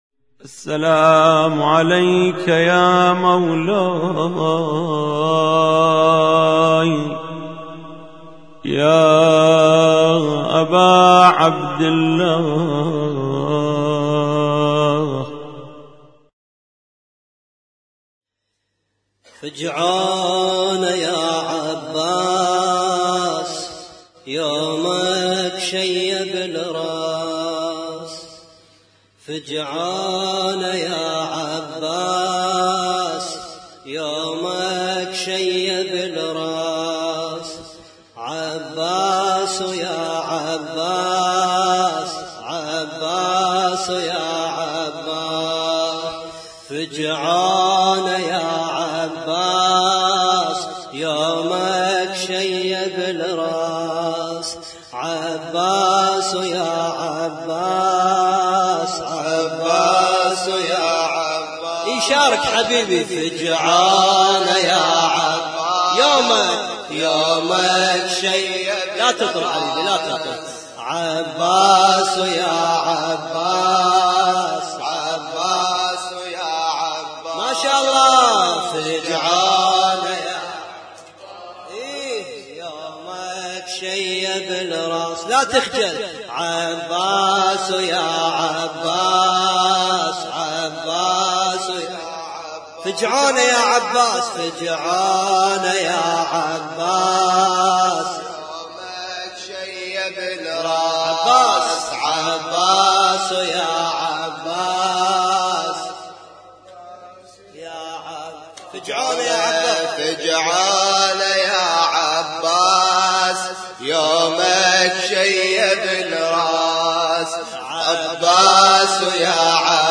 اسم النشيد:: لطم مشترك - ليلة 7 محرم 1436